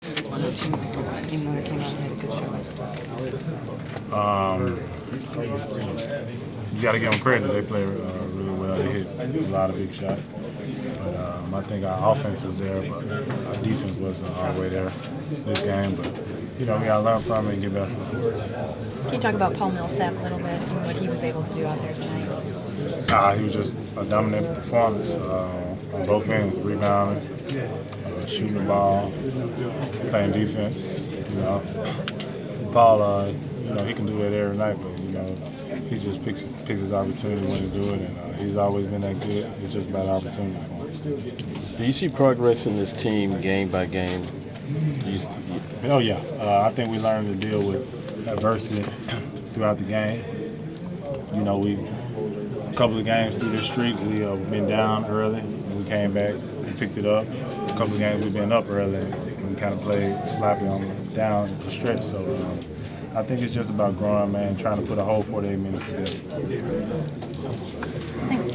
Inside the Inquirer: Postgame interview with Atlanta Hawks’ DeMarre Carroll (1/28/15)
We attended the post-game interview of Atlanta Hawks’ forward DeMarre Carroll following his team’s 113-102 home win over the Brooklyn Nets. The victory extended the Hawks’ winning streak to a franchise-record 17 games and the team is now 31-2 over its last 33 contests.